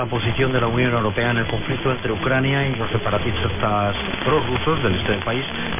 可以听到与主要传输重叠的中间站。
Tag: 声音 西班牙语 收音机 特温特 大学 AM 新闻阅读器